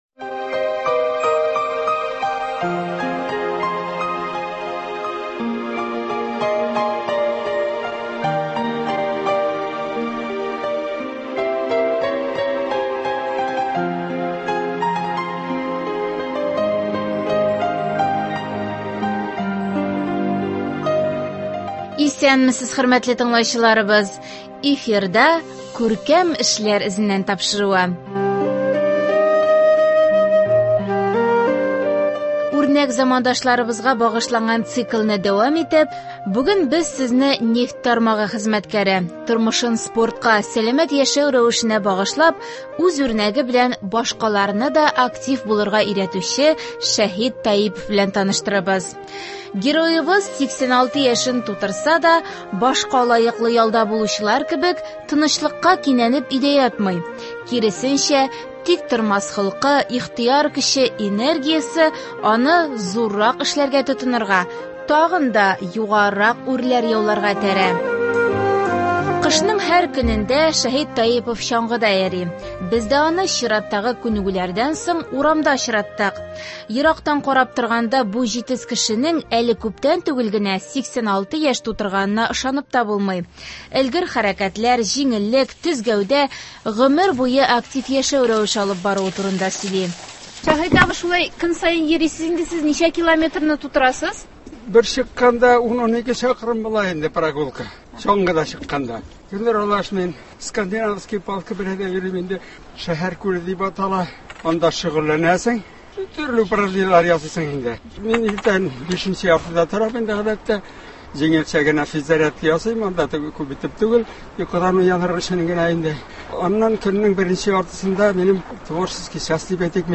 Студия кунагы